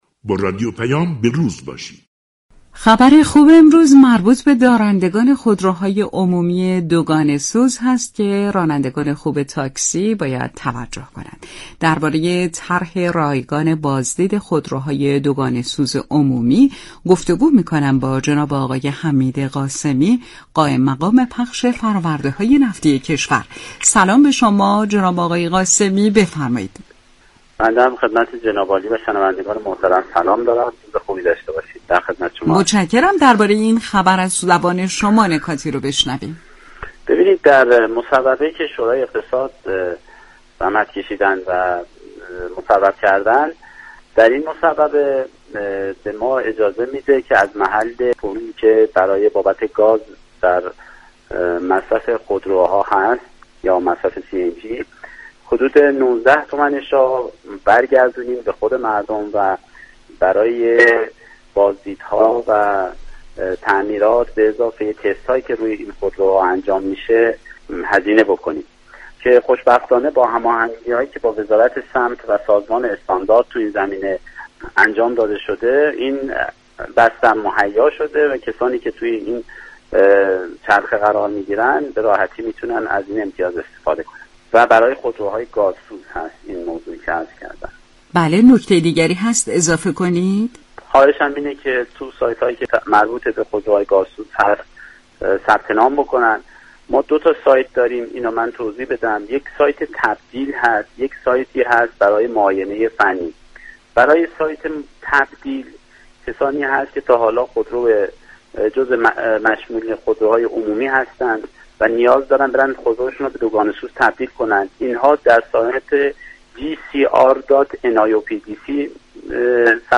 در گفتگو با رادیو پیام